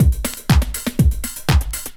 Phaze 1 Beat 2_121.wav